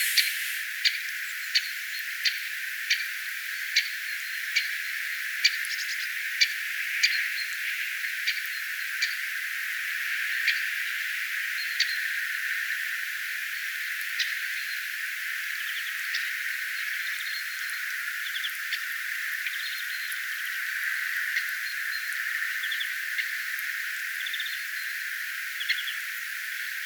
tuollaisia käpytikan ääniä
tuollaisia_kapytikan_aania.mp3